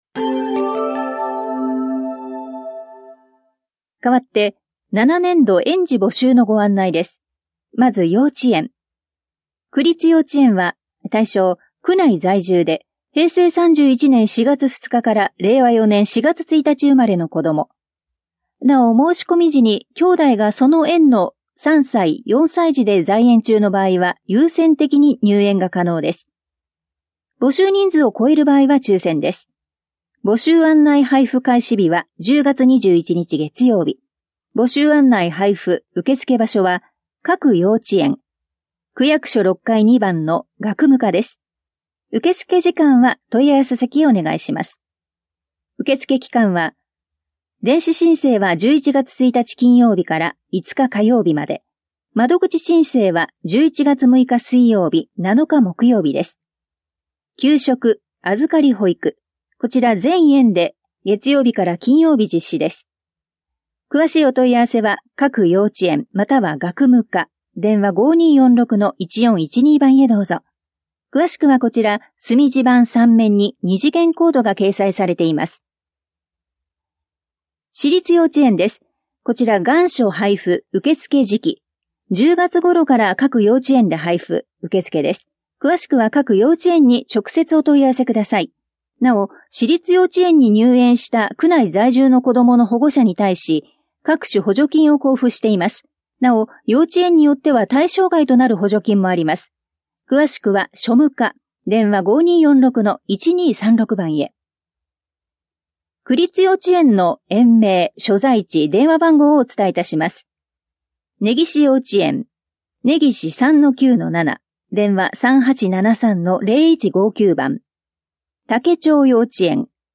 広報「たいとう」令和6年10月5日号の音声読み上げデータです。